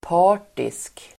Uttal: [p'a:r_tisk]